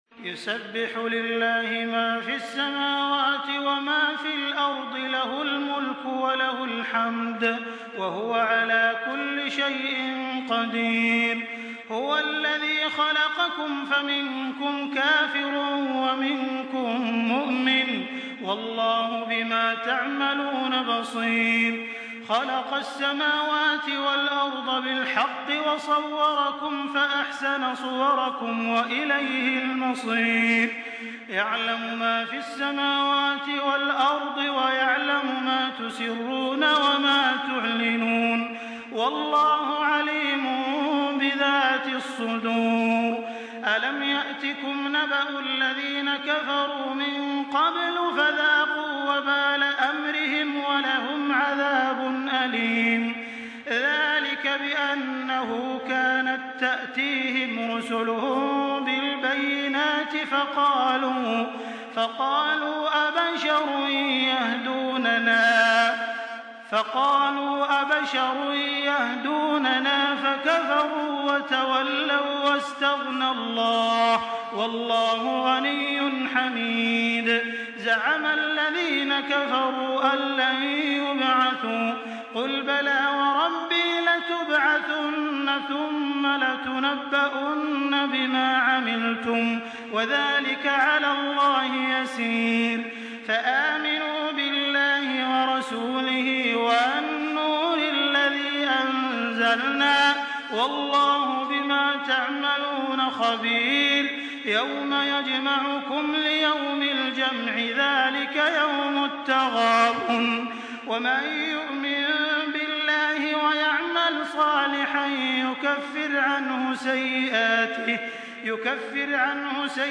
Surah Tegabun MP3 by Makkah Taraweeh 1424 in Hafs An Asim narration.
Murattal